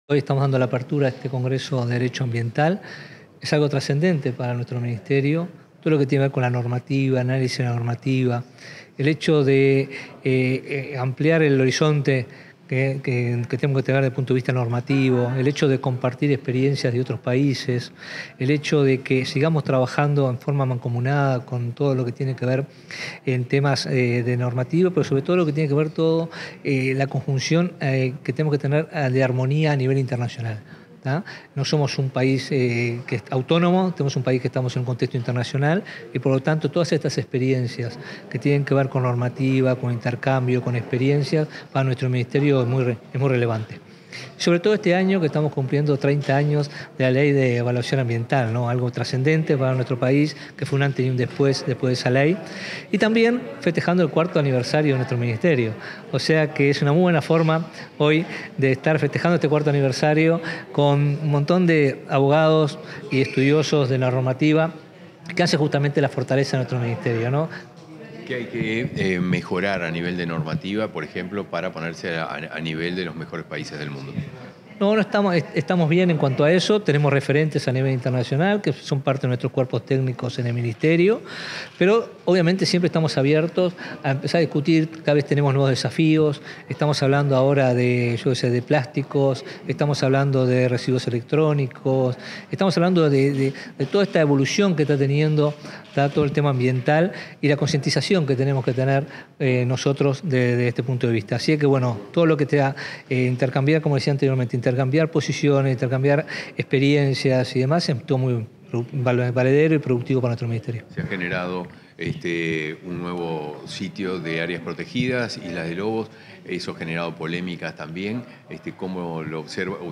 Declaraciones del ministro de Ambiente, Robert Bouvier 27/08/2024 Compartir Facebook X Copiar enlace WhatsApp LinkedIn En el marco del tercer Congreso Uruguayo de Derecho Ambiental, este 27 de agosto, el ministro de Ambiente, Robert Bouvier, realizó declaraciones a la prensa.